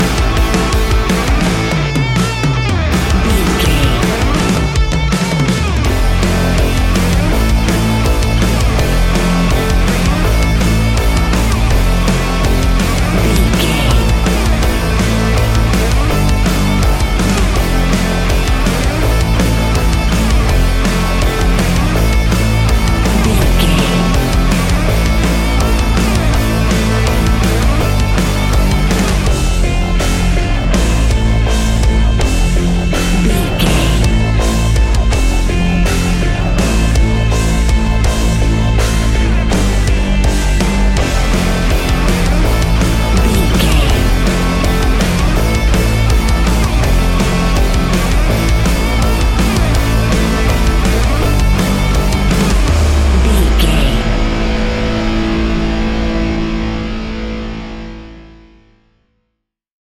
Ionian/Major
A♭
hard rock
heavy metal